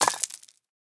stonefail1.ogg